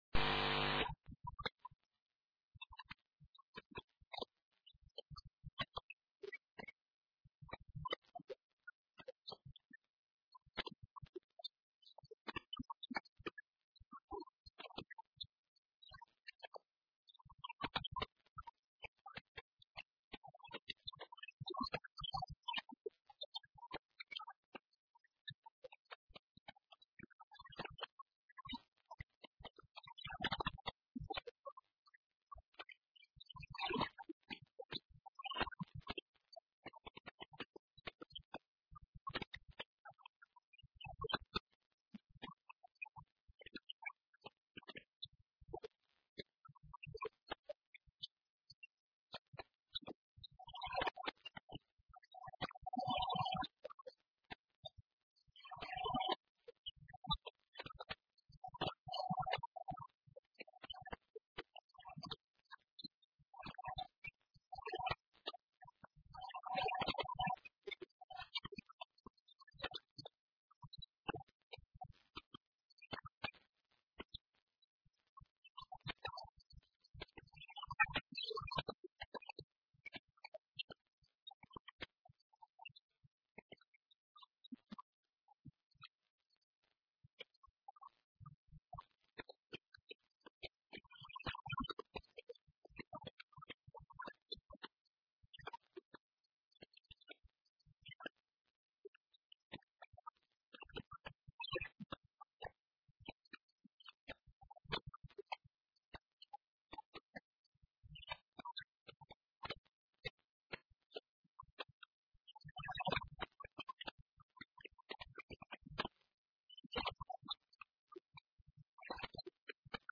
O Secretário Regional da Educação, Ciência e Cultura reiterou hoje, na Assembleia Legislativa, na Horta, que as bolsas de investigação científica “que estão contratualizadas são para cumprir”.